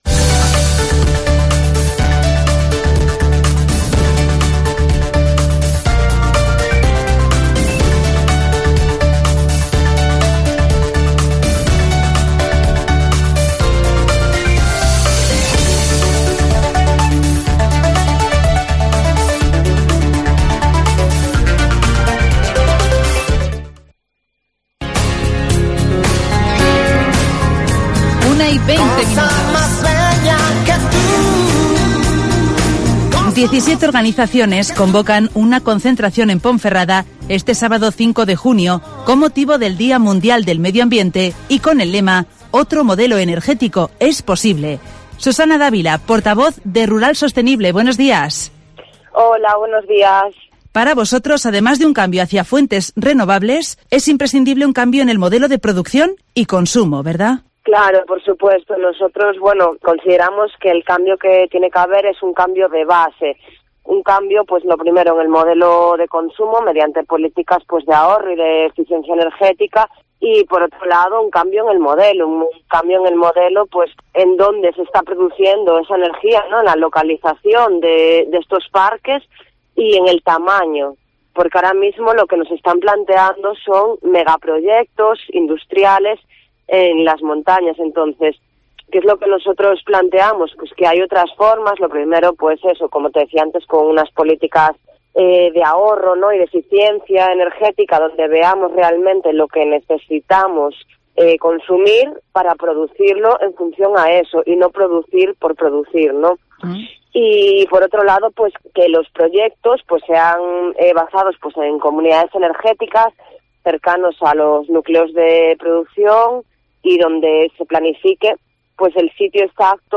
17 organizaciones convocan una concentración en Ponferrada bajo el lema Otro modelo energético es posible (Entrevista